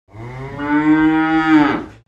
دانلود آهنگ مزرعه 15 از افکت صوتی طبیعت و محیط
دانلود صدای مزرعه 15 از ساعد نیوز با لینک مستقیم و کیفیت بالا
برچسب: دانلود آهنگ های افکت صوتی طبیعت و محیط دانلود آلبوم صدای مزرعه روستایی از افکت صوتی طبیعت و محیط